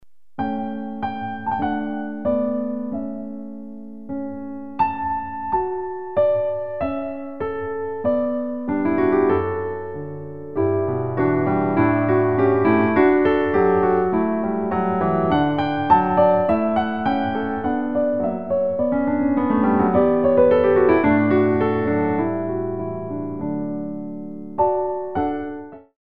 45 selections (67 minutes) of Original Piano Music